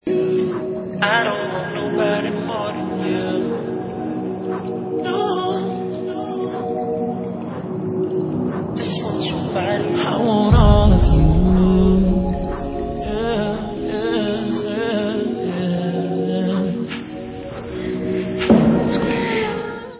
sledovat novinky v oddělení Pop/Rhytm & Blues